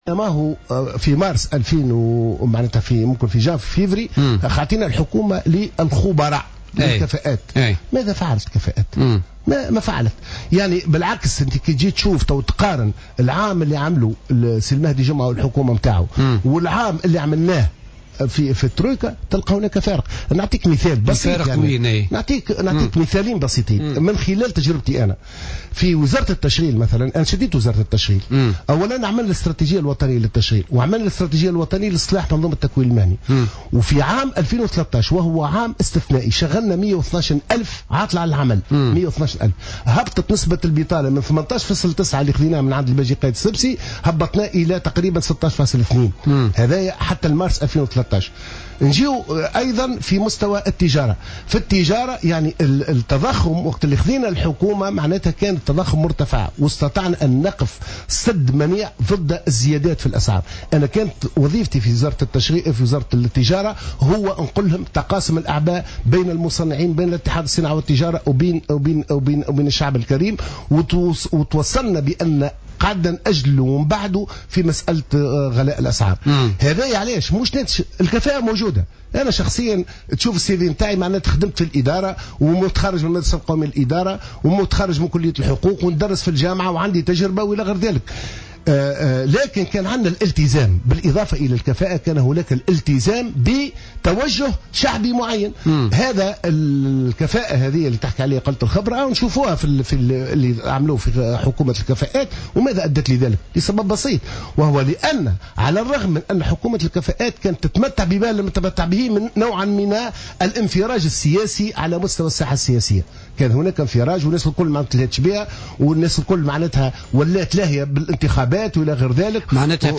وتساءل معطر، ضيف بوليتيكا على جوهرة أف أم، عن إنجازات حكومة الكفاءات في هذا المجال مقارنة بحكومة الترويكا بعد سنة من تولي حكومة المهدي جمعة المسؤولية والتي لم تنجح في تحقيق أية انجازات تذكر.